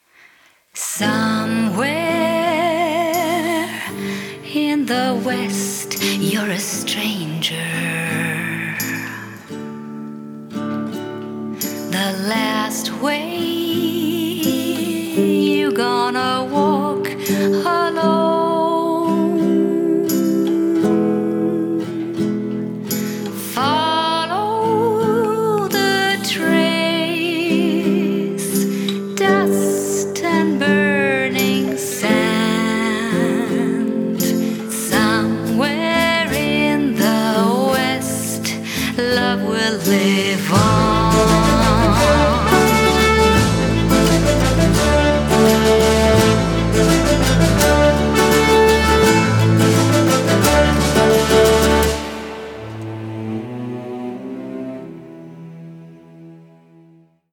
Filmmusik